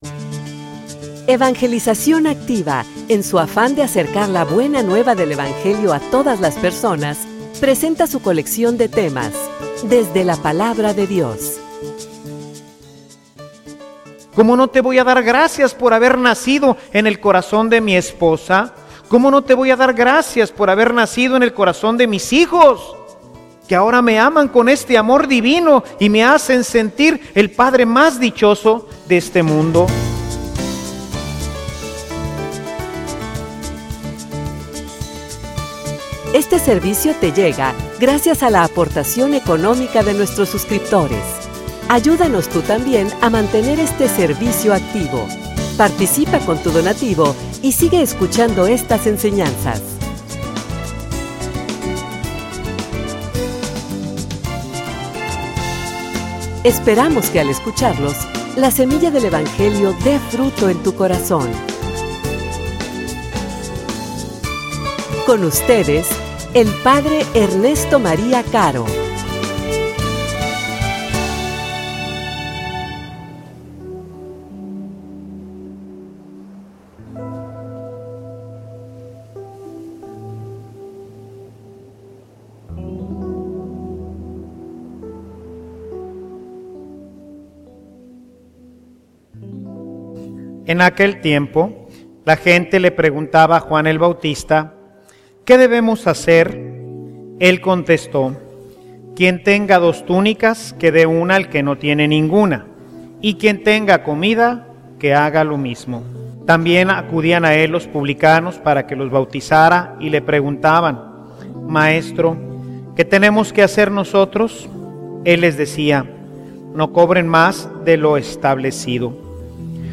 homilia_La_razon_de_nuestra_celebracion.mp3